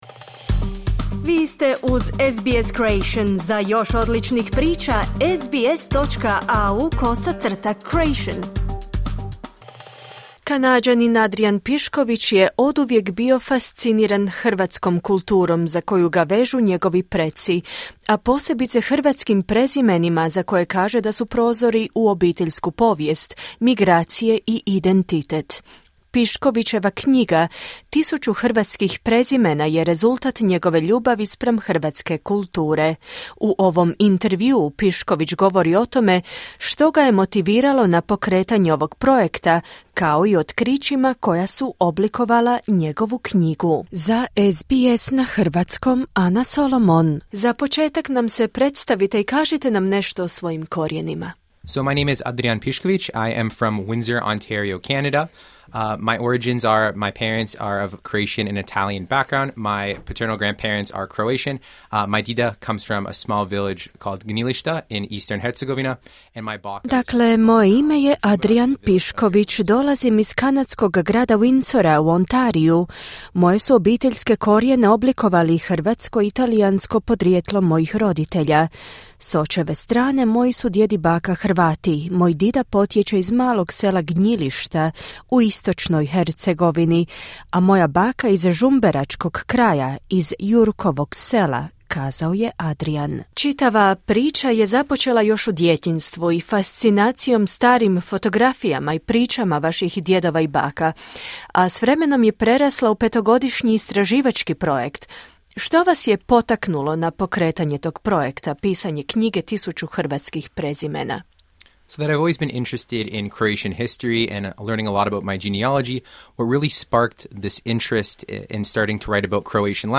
U ovom intervjuu